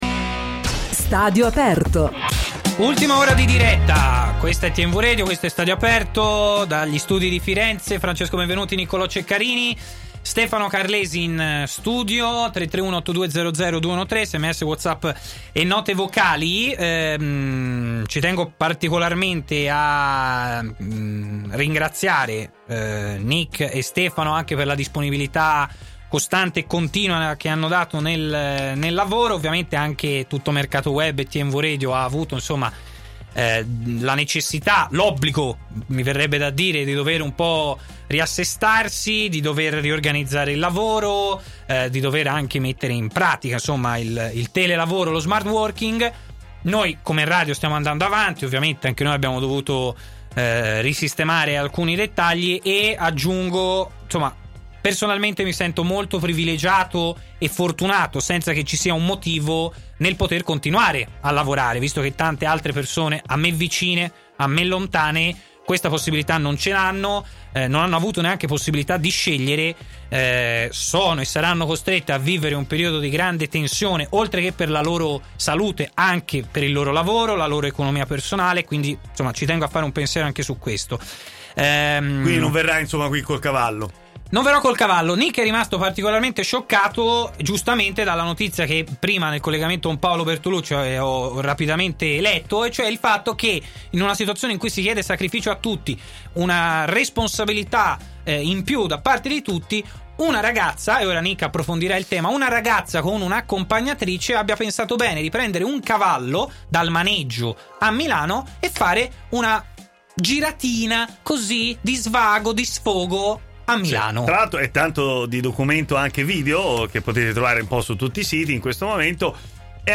Antonio Di Gennaro interviene a “Stadio Aperto” su TMW Radio per parlare del modo in cui l’Italia e l’Europa stanno affrontando l’emergenza Coronavirus.